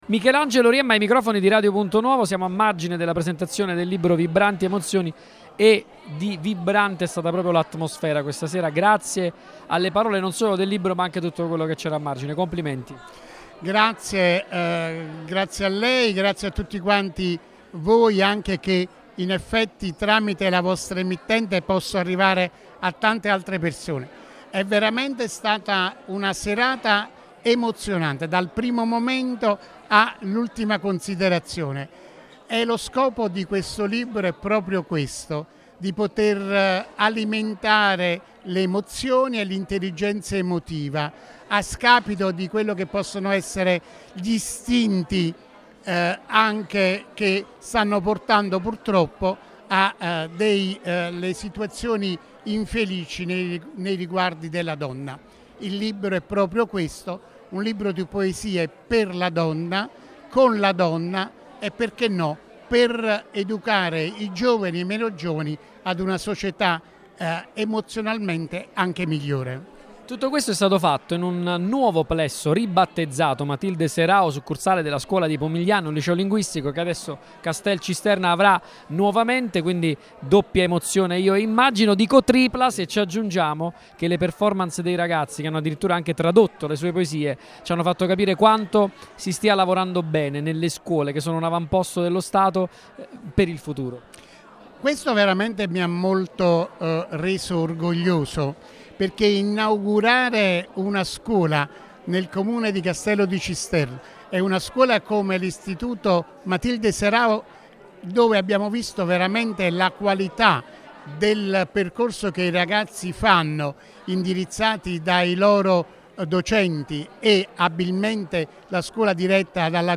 Radio Punto Nuovo, in qualità di radio partner dell’iniziativa, era presente alla presentazione e alla conferenza stampa, e sarà oggi e domani, 24 e 25 ottobre, presente con la regia mobile dall’Auditorium Enrico De Nicola, a partire dalle ore 18:00, per raccontare il convegno odierno e la premiazione dei vincitori.